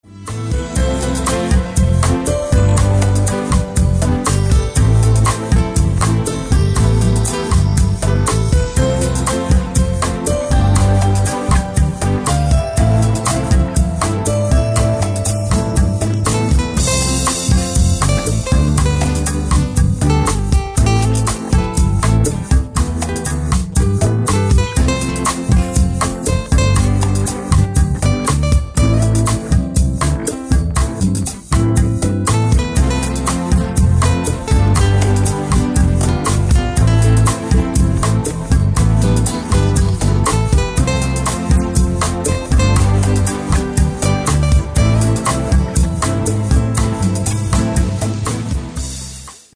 • Качество: 128, Stereo
гитара
спокойные
без слов
релакс
клавишные
Стиль: lounge, chillout